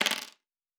Dice Multiple 3.wav